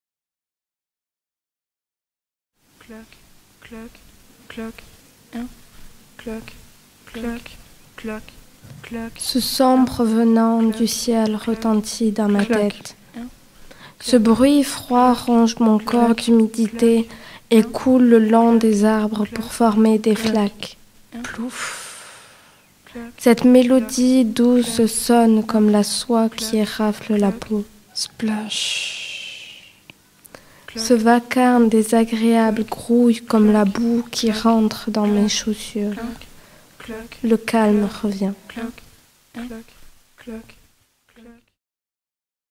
Archive 2022 : Poèmes bruités